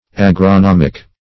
Agronomic \Ag`ro*nom"ic\, Agronomical \Ag`ro*nom"ic*al\, [Cf. F.